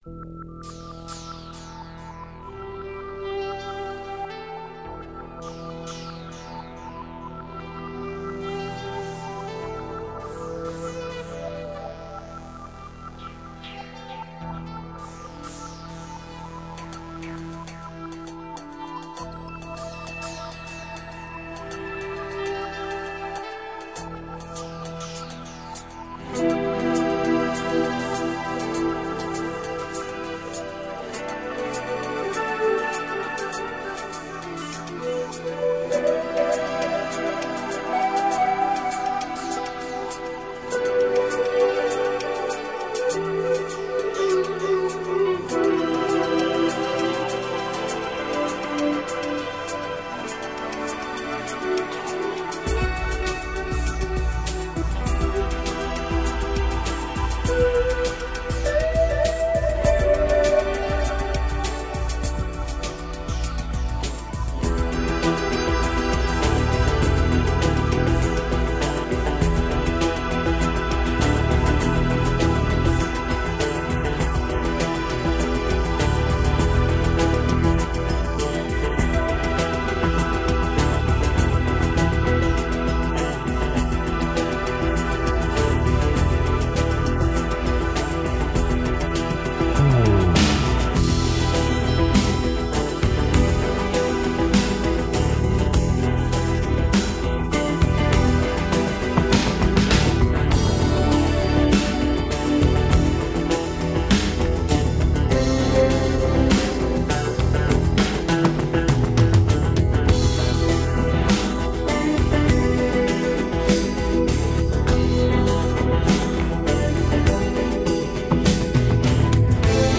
Download Posted in Instrumentals .